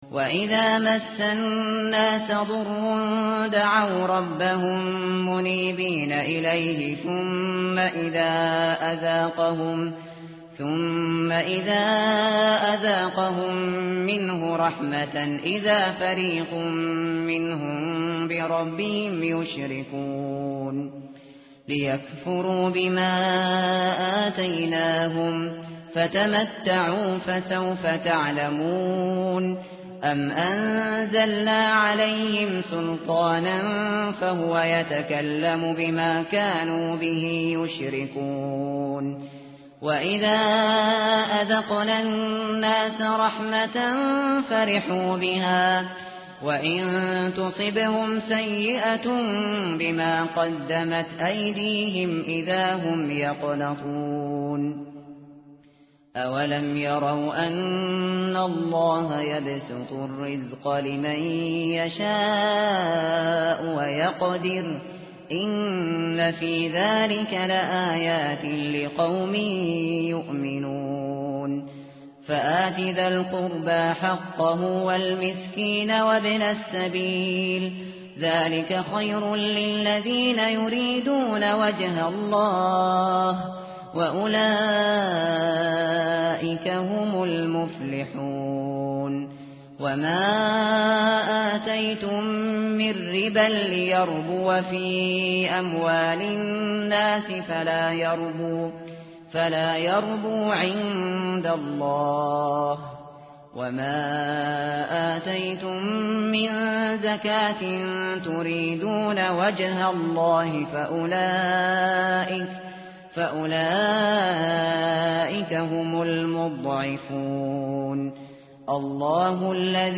متن قرآن همراه باتلاوت قرآن و ترجمه
tartil_shateri_page_408.mp3